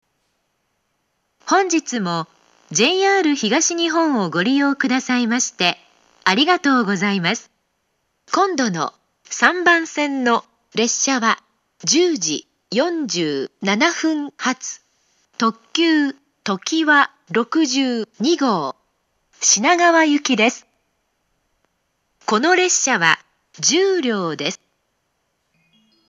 ３番線到着予告放送